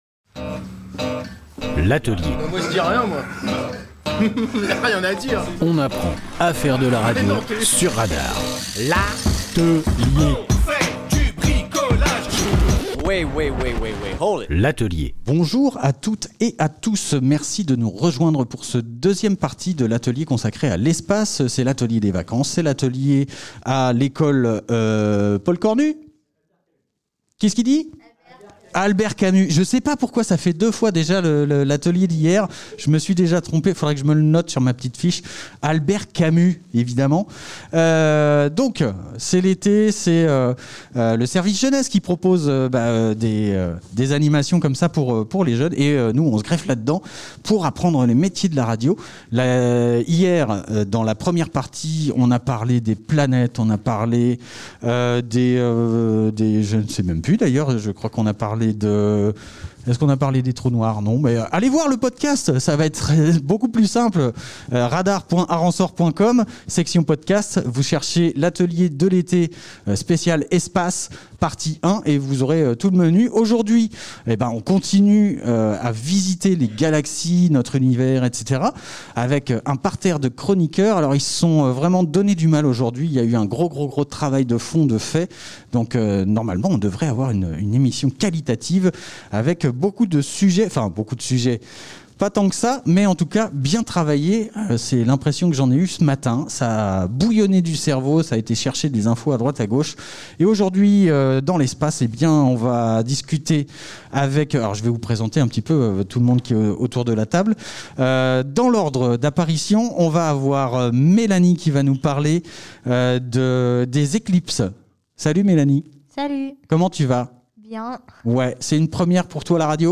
On y apprend à faire de la radio de manière ludique.
La majorité des participants n'ont jamais fait de radio et s'essaye à l'exercice. Et bien qu'on consacre ce temps d'antenne plus souvent aux enfants, les adultes peuvent aussi y participer.